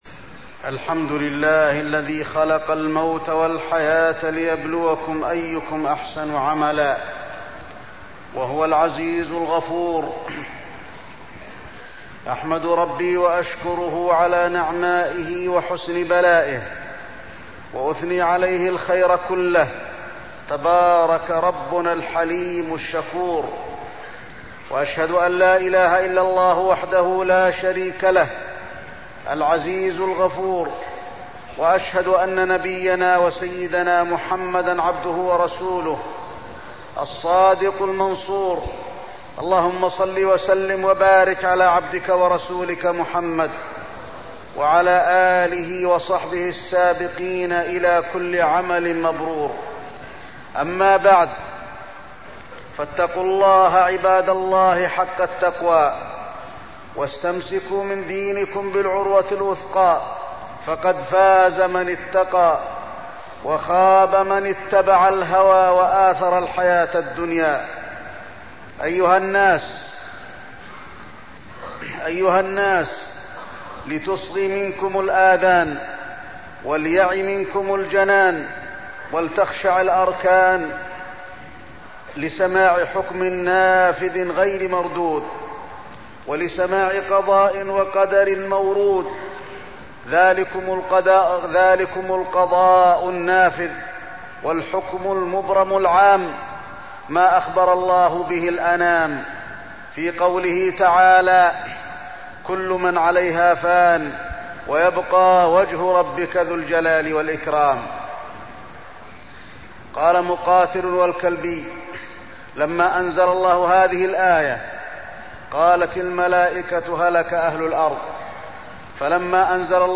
تاريخ النشر ١٧ جمادى الآخرة ١٤١٦ هـ المكان: المسجد النبوي الشيخ: فضيلة الشيخ د. علي بن عبدالرحمن الحذيفي فضيلة الشيخ د. علي بن عبدالرحمن الحذيفي الموت